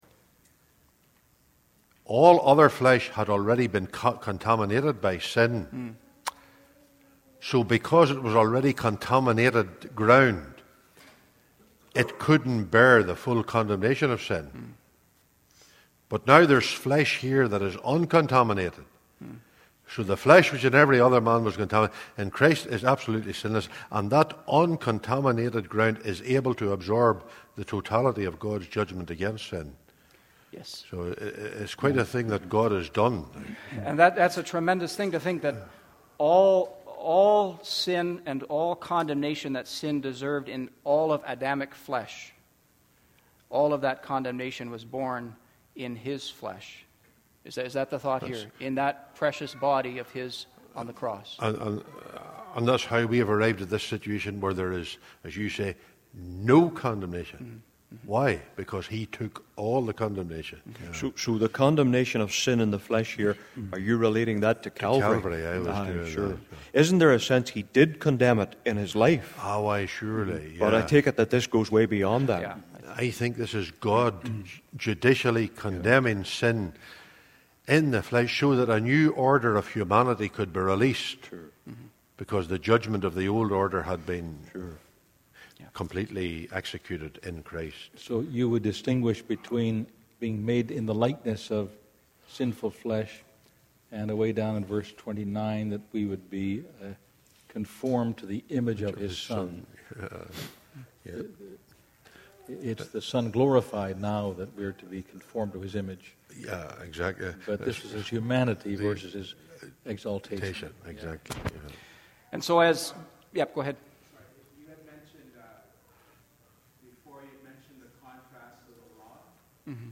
Bible Reading Conference 2019